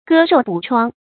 割肉补疮 gē ròu bǔ chuāng
割肉补疮发音
成语注音 ㄍㄜ ㄖㄡˋ ㄅㄨˇ ㄔㄨㄤ